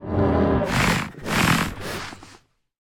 Minecraft Version Minecraft Version latest Latest Release | Latest Snapshot latest / assets / minecraft / sounds / mob / warden / sniff_1.ogg Compare With Compare With Latest Release | Latest Snapshot
sniff_1.ogg